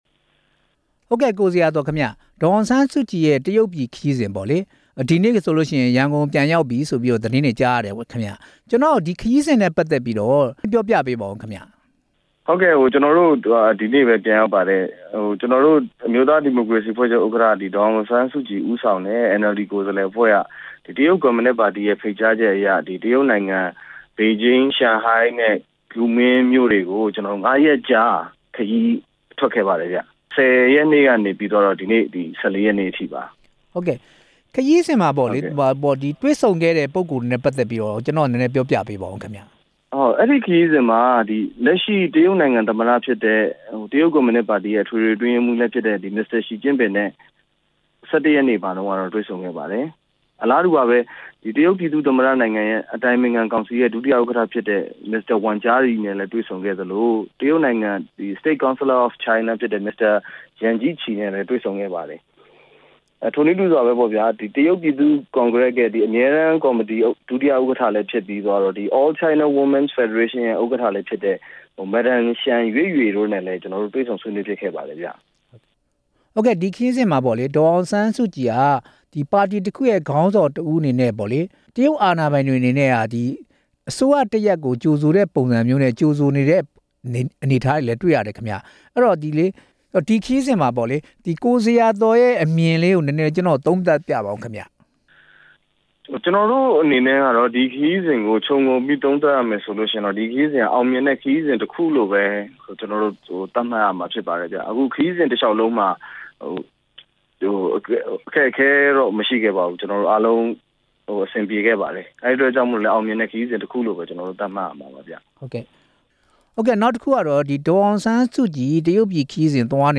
ဒေါ်အောင်ဆန်းစုကြည်ရဲ့ တရုတ်ခရီးစဉ် အကြောင်း မေးမြန်းချက်